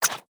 Tab Select 7.wav